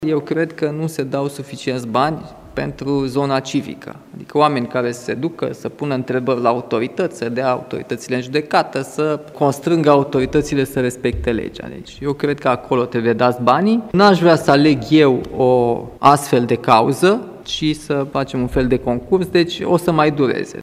Președintele României, Nicușor Dan: „Eu cred că nu se dau suficienți bani pentru zona civică”